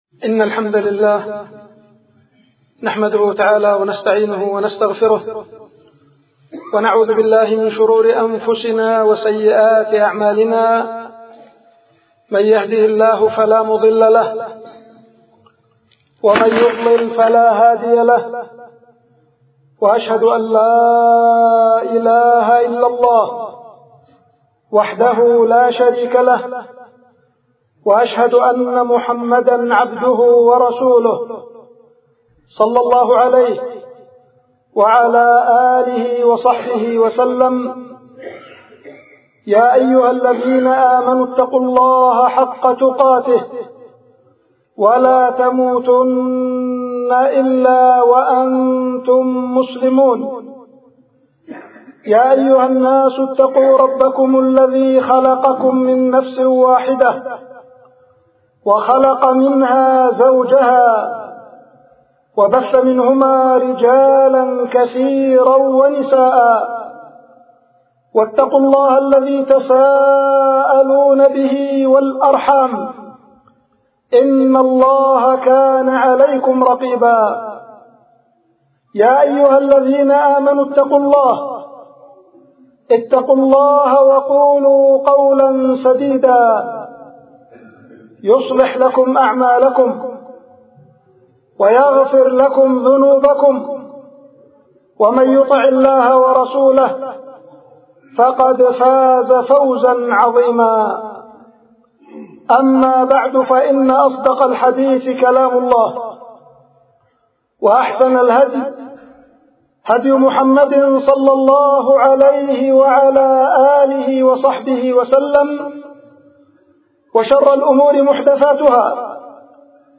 خطبة
ألقيت في الجامع الكبير -بالعزلة-بعدان-إب-اليمن